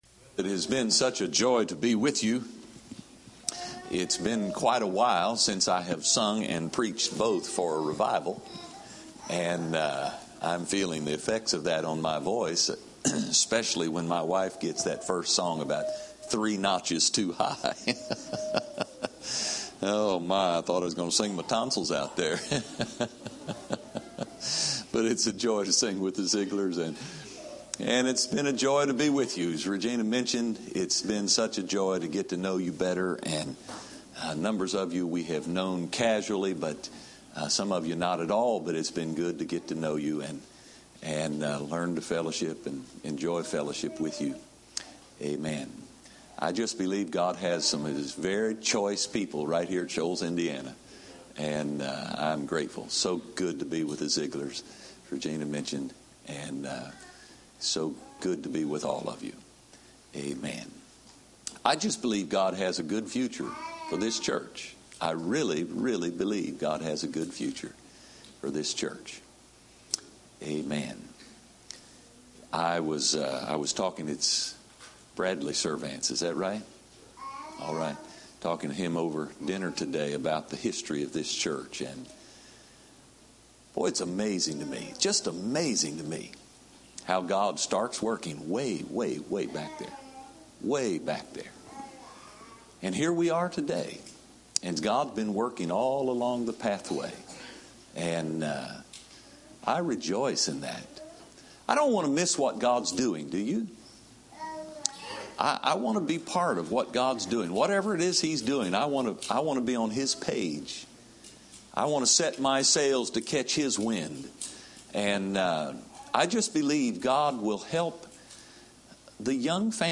Save Audio A message